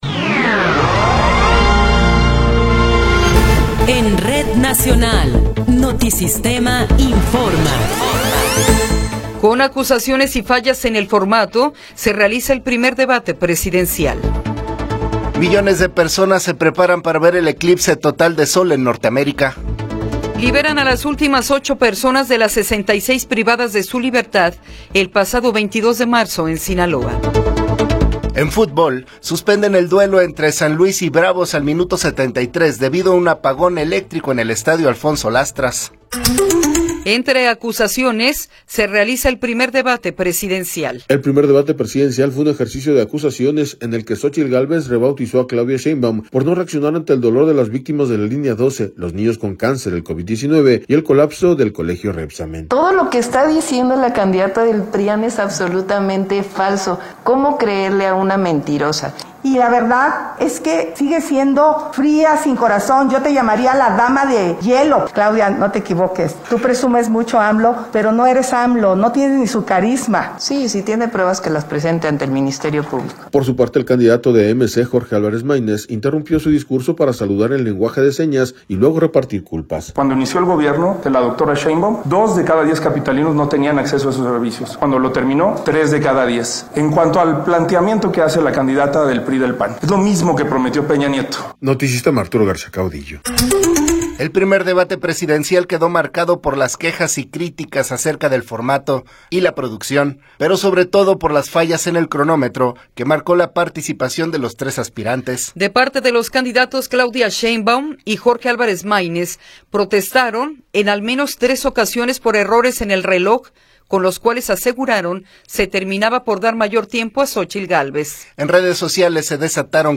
Noticiero 8 hrs. – 8 de Abril de 2024
Resumen informativo Notisistema, la mejor y más completa información cada hora en la hora.